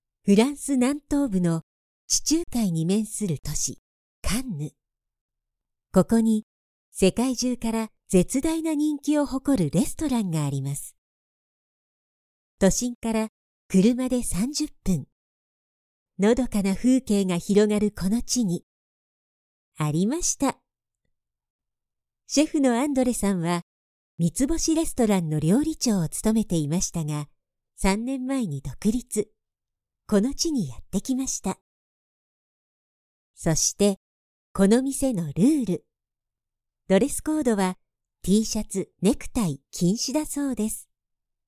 テレビ・ラジオ、CM、企業VP、ゲーム、施設内放送など、さまざまなジャンルで多くの実績があり、明るく爽やかなもの、堅く落ち着いたもの、優しい語りまで、幅広く対応可能です。
– ナレーション –
female109_27.mp3